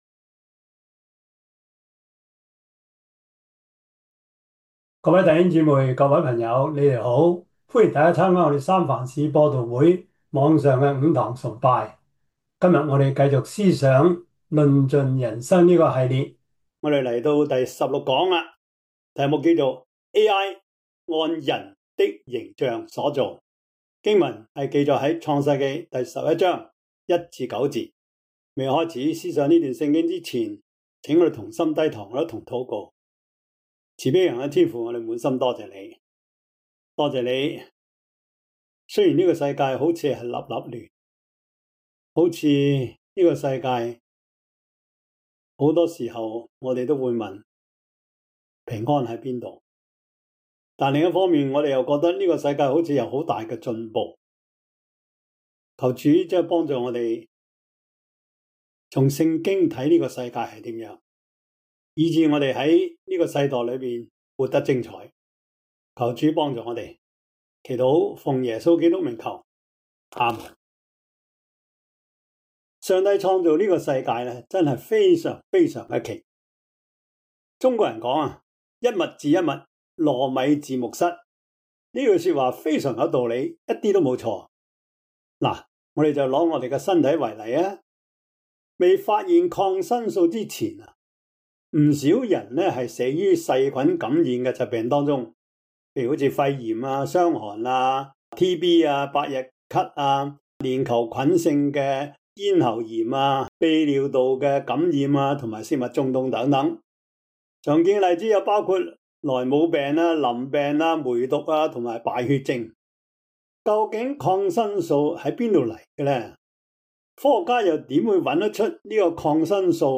創世記 11:1-9 Service Type: 主日崇拜 創世記 11:1-9 Chinese Union Version
Topics: 主日證道 « 名存實亡 至死忠心 »